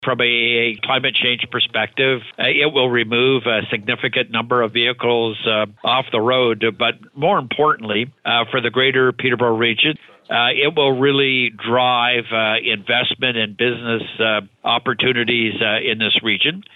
Mayor Jeff Leal says this is a very exciting development.